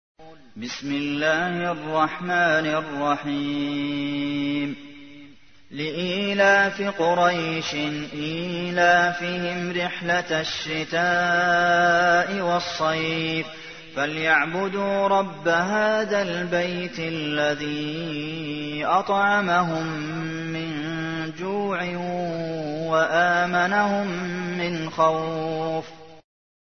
تحميل : 106. سورة قريش / القارئ عبد المحسن قاسم / القرآن الكريم / موقع يا حسين